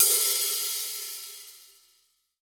14HK OPEN.wav